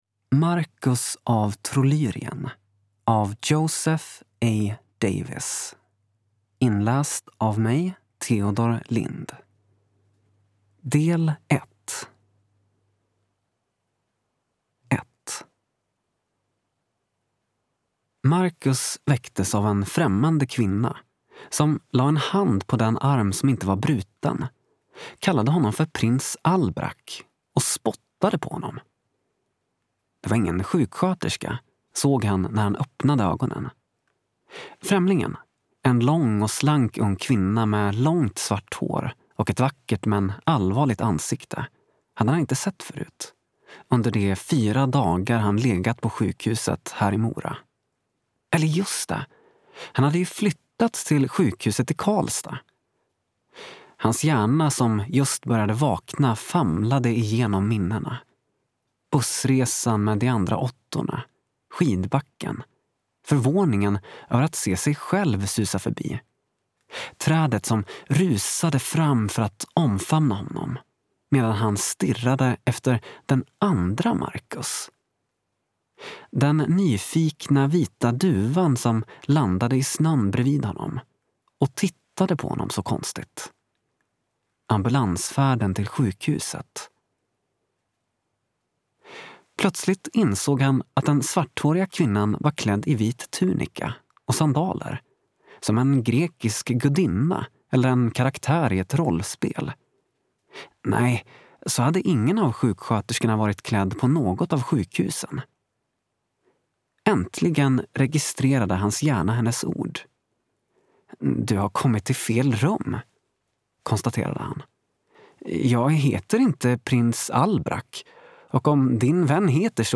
Provlyssna på ljudboken här (kapitel 1-4)